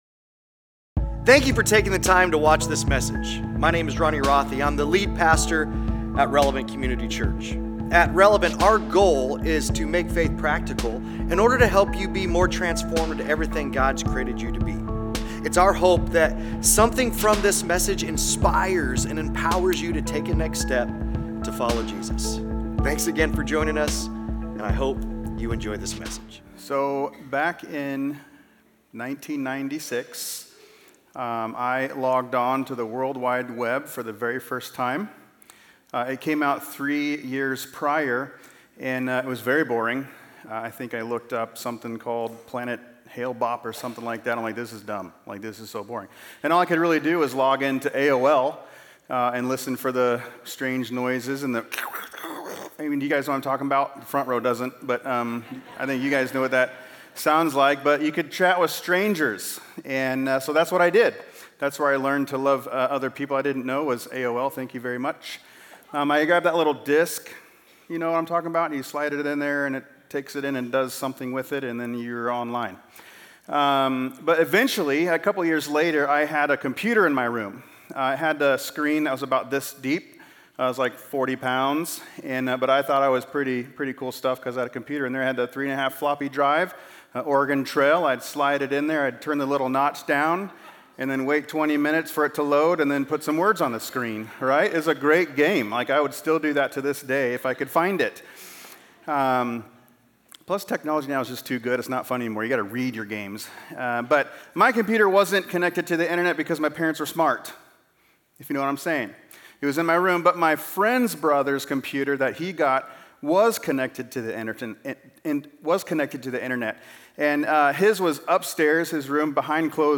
Sunday Sermons PRAY, Part 2: "Repent" Mar 02 2025 | 00:38:49 1x 00:00 / 00:38:49 Subscribe Share Apple Podcasts Spotify Overcast RSS Feed Share Link Embed